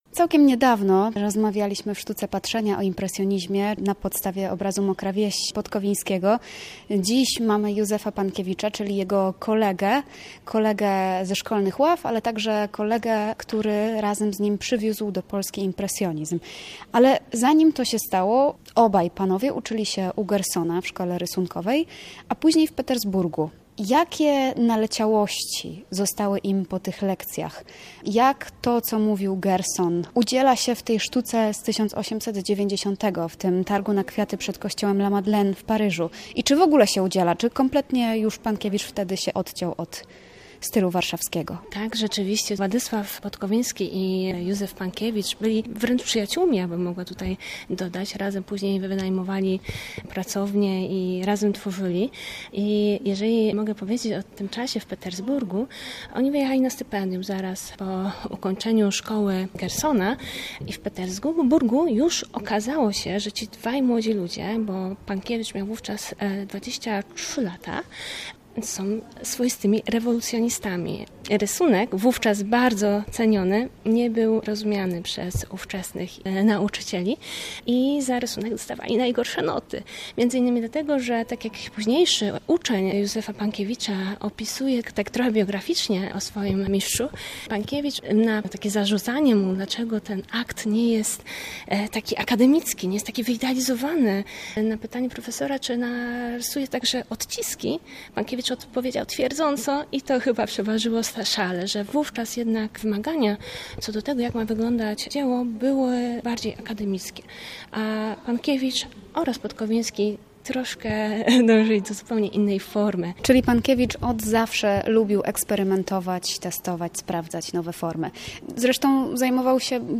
Cykl Sztuka Patrzenia emitowany jest co niedzielę w programie Spis Treści między godziną 13 a 15.